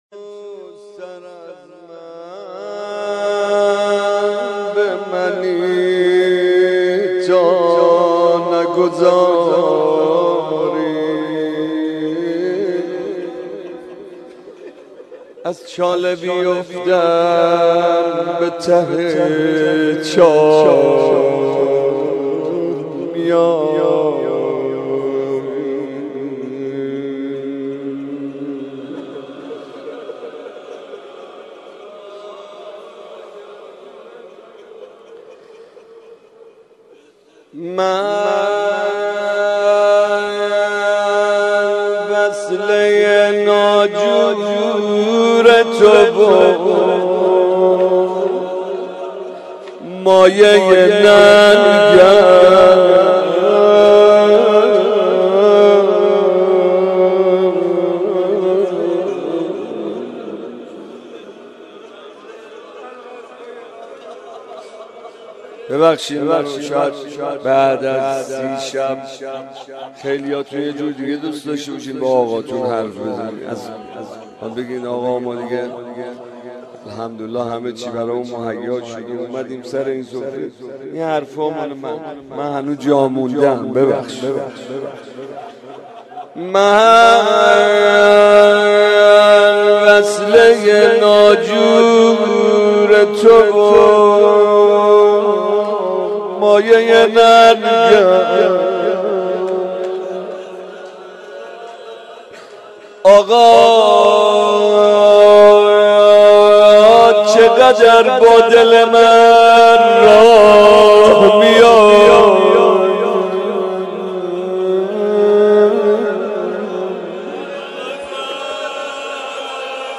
مناسبت : شب سی ام رمضان
قالب : غزل